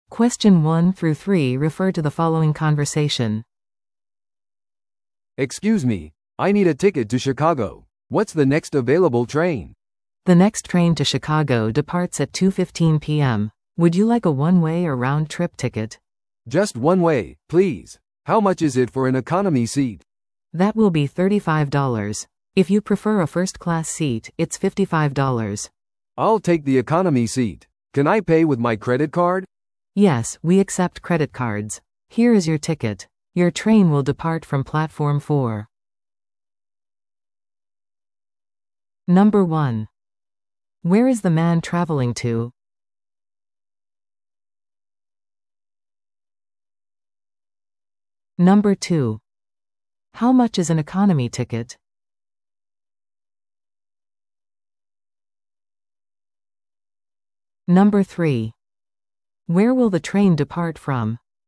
TOEICⓇ対策 Part 3｜Train Ticket Purchase Conversation – 音声付き No.75